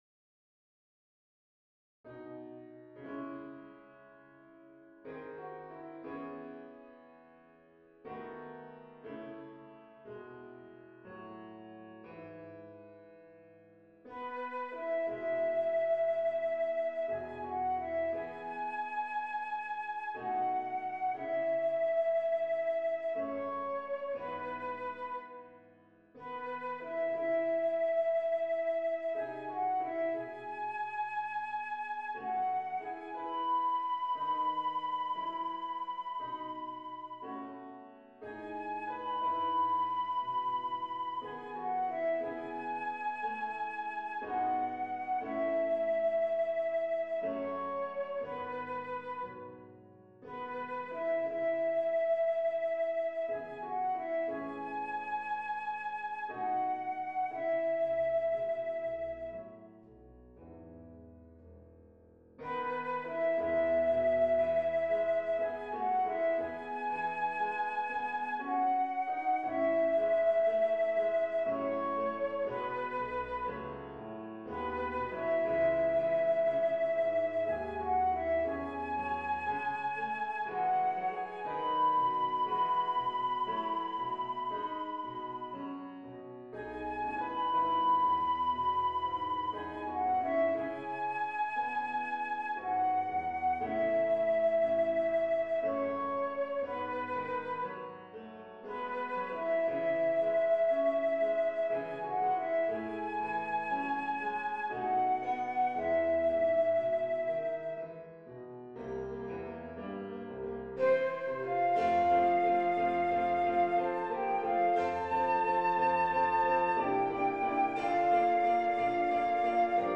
ゆったりと優雅に流れる旋律、リズムを取りやすい３拍子感。
楽譜を見ると真っ先に飛び込んでくるのが＃（シャープ）が４つもつくホ長調。
・ゆったりしたテンポ
・同じ旋律が何度も繰り返しされる曲構成
後半分では♭（フラット）１つのヘ長調に転調するクライマックス。